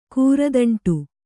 ♪ kūradaṇṭu